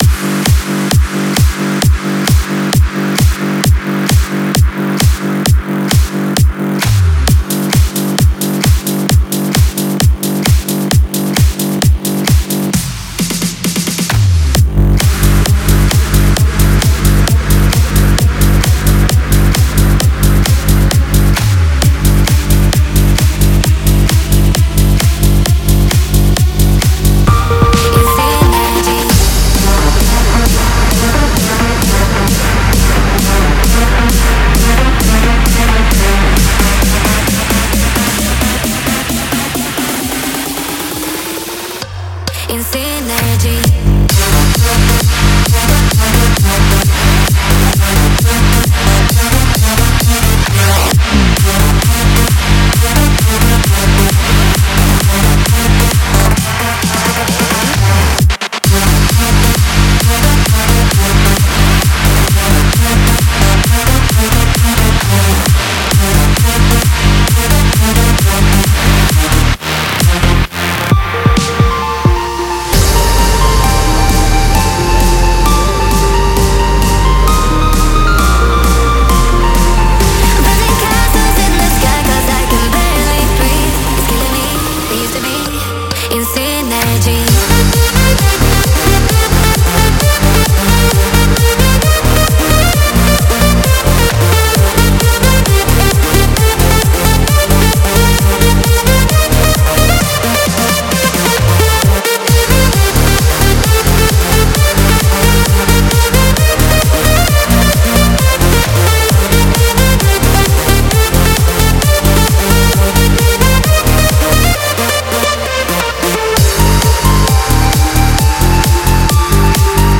试听文件为低音质，下载后为无水印高音质文件 M币 15 超级会员 M币 7 购买下载 您当前未登录！